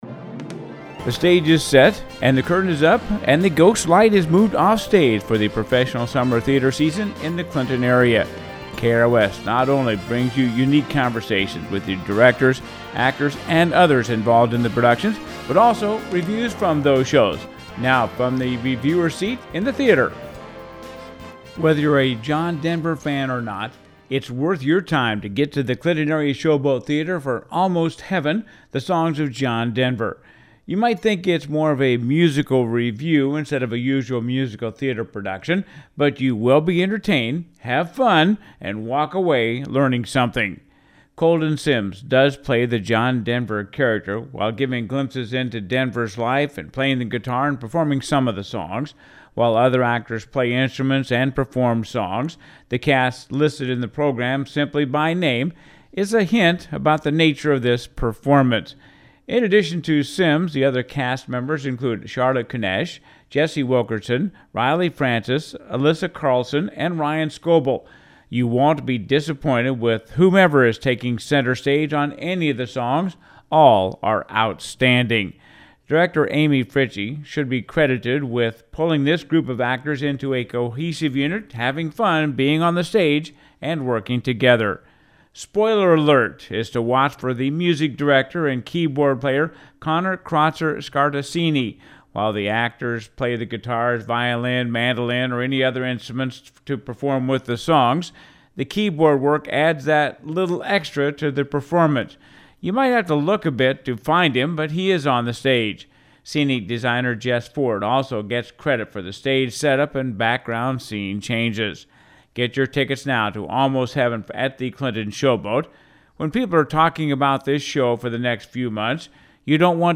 KROS Review of Almost Heaven now on stage on the Clinton Showboat
Almost Heaven Review